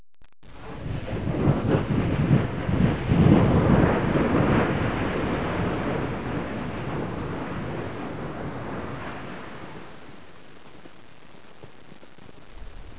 Disaster Sound Effects
Volcanos
volcano-01.wav